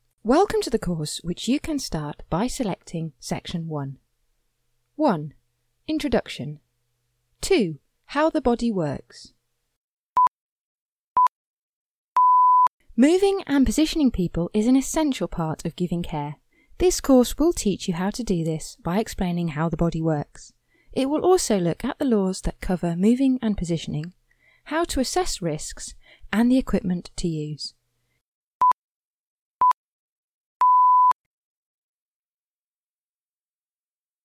With access to my own studio I can record Voiceovers for a variety of purposes at short notice, and even provide bespoke samples on request to meet the style you need for your project.
Showreel
eLearning – Health Care